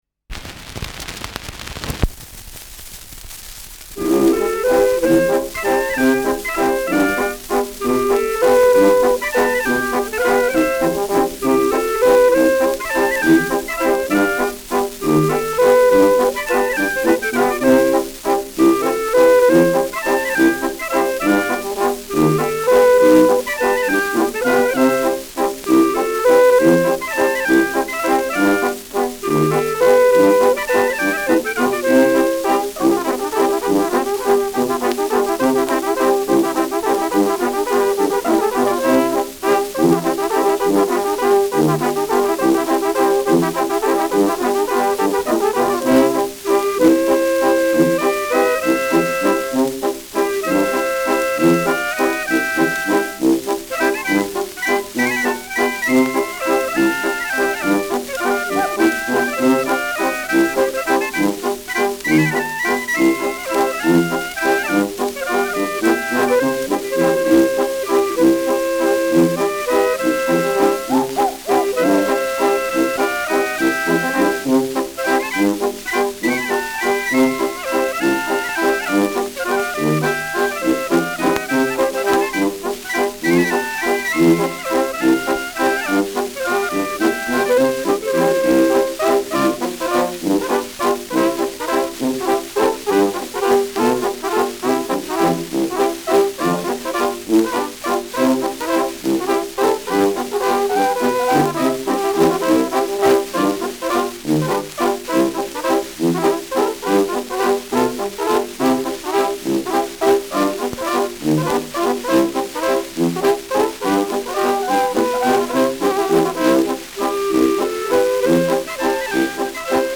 Schellackplatte
Tonrille: Kratzer 10 Uhr Leicht
Zum Ende dumpfer
[Nürnberg] (Aufnahmeort)